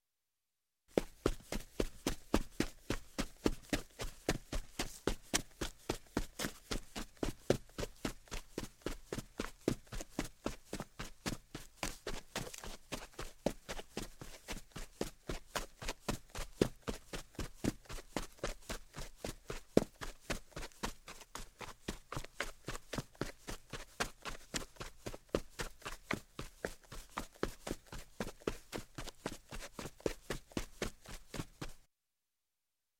Звуки шагов по земле
На этой странице собрана коллекция реалистичных звуков шагов по различным типам земной поверхности.
Звук бега по земле